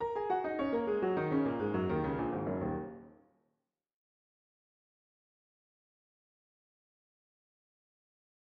Pour  terminer ce passage, Ravel va utiliser une gamme pentatonique de ré dièse, tout d’abord aux vents puis au piano.
penta-piano-re-dic3a8se.mp3